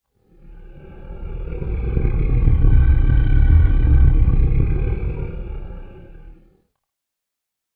SFX鬼呼吸声音效下载
SFX音效